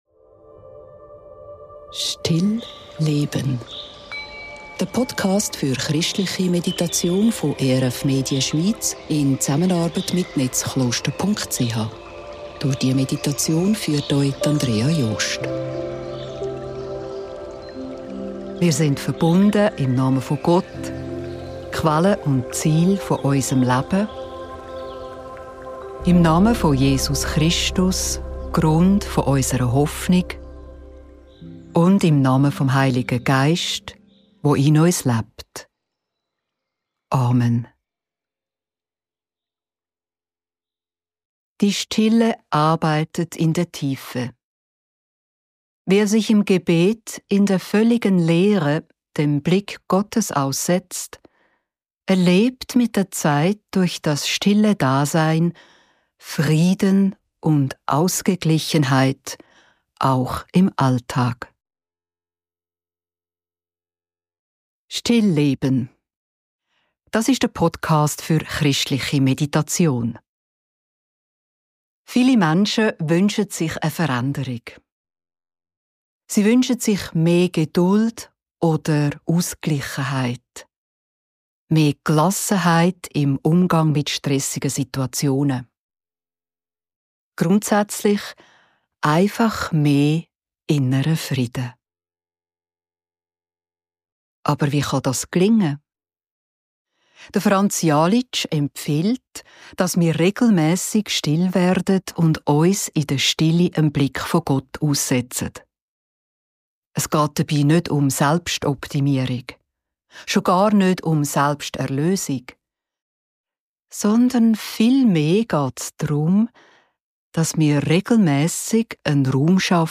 In der geführten Meditation wenden wir uns einer Geschichte eines unbekannten Mönchs aus dem 11. Jahrhundert zu.